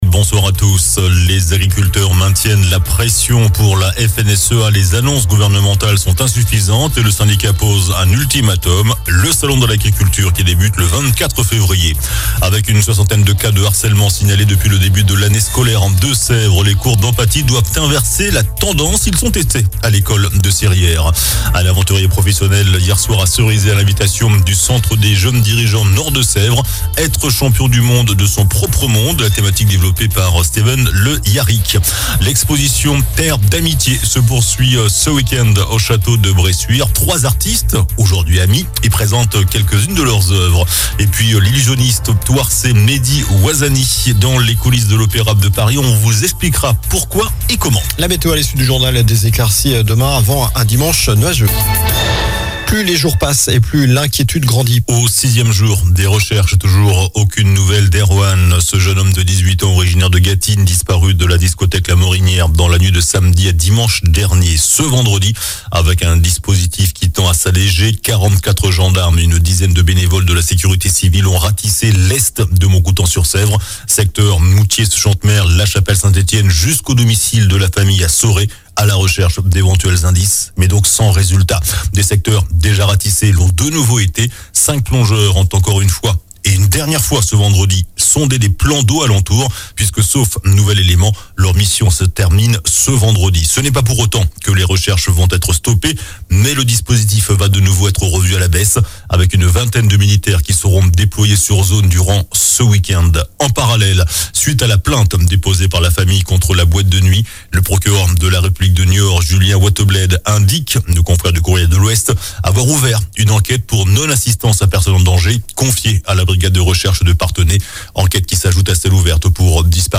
JOURNAL DU VENDREDI 16 FEVRIER ( SOIR )